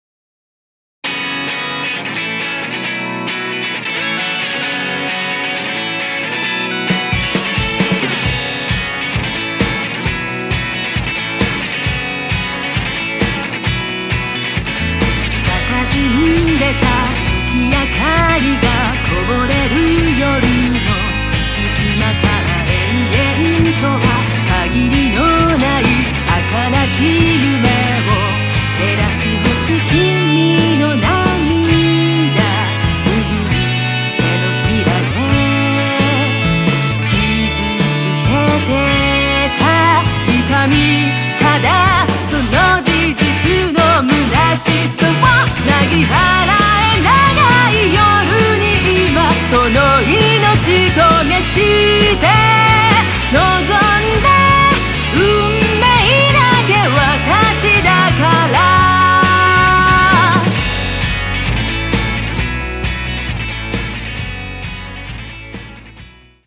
クロスフェードデモ
歌！唄！謡！の、風流ポップスここにあり！